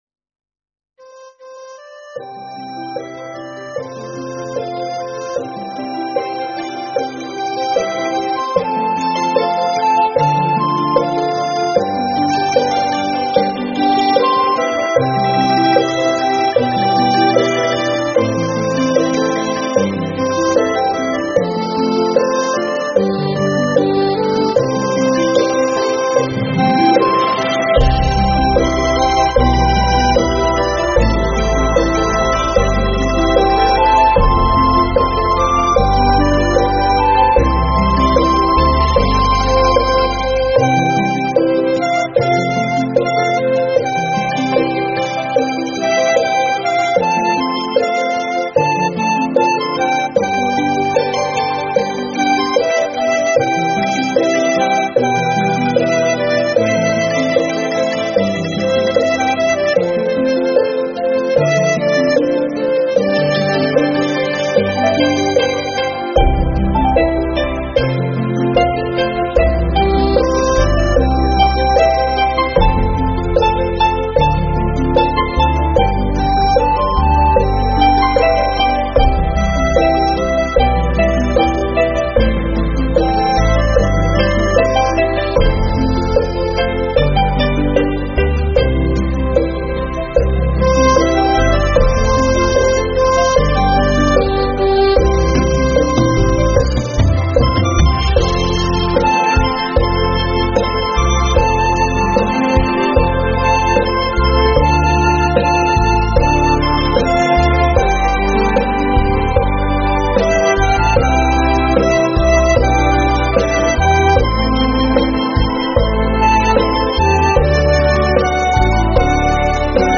tại Chùa Hải Đức